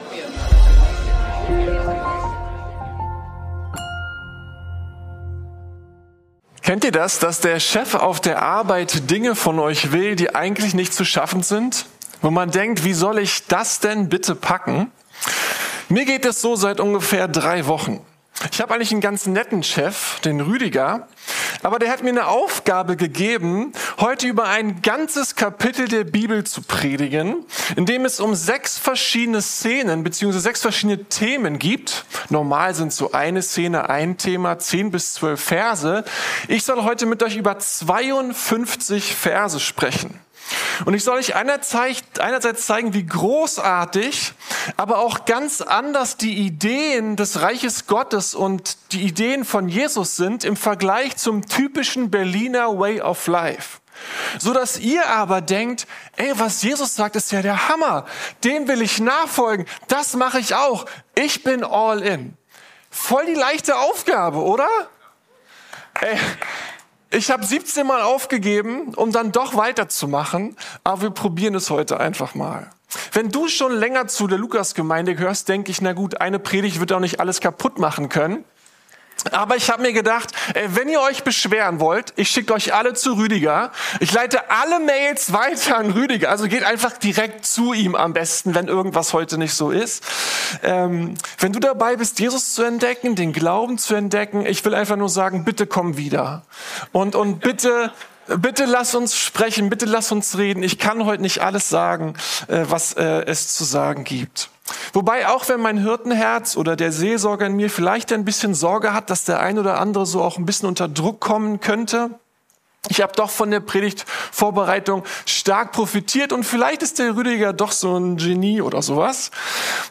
Der Erfolg in der Nachfolge ~ Predigten der LUKAS GEMEINDE Podcast